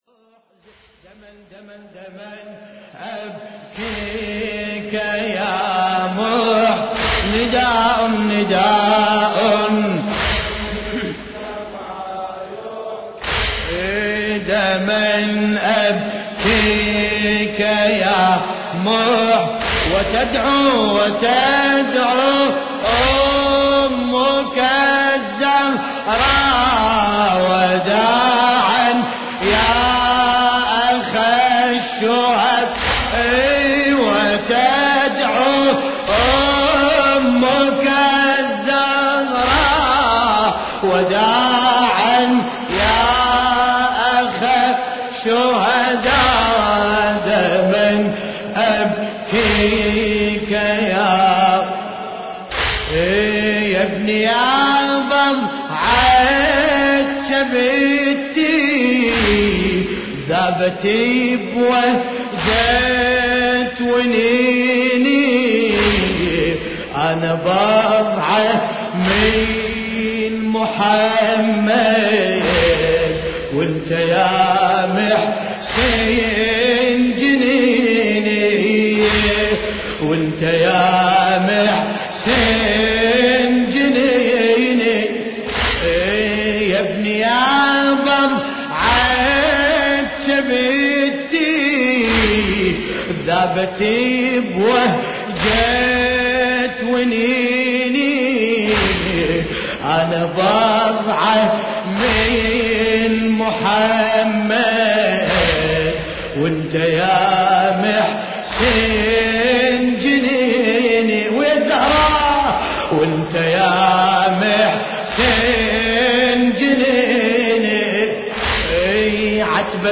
مداحی «دما أبکیک یا محسن» ، فاطمیه 1432 با صدای ملاباسم کربلایی به زبان عربی (27:29)